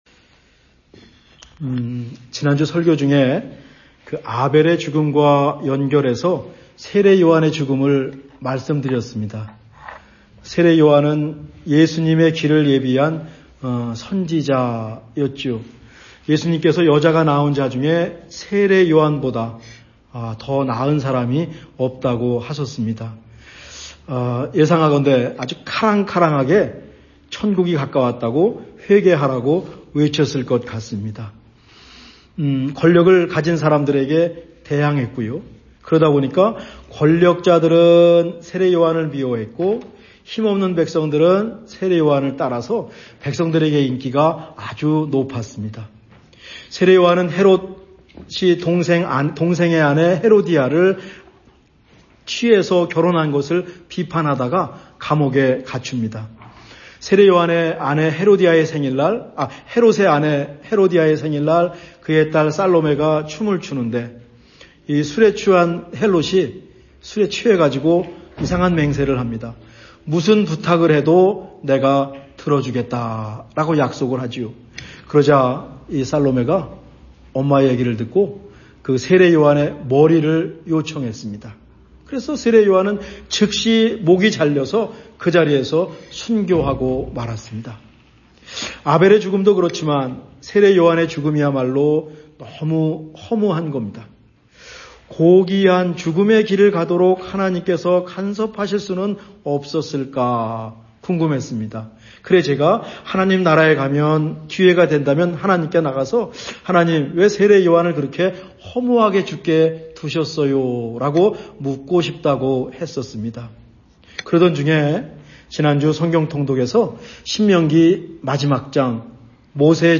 2026년 3월 2주 말씀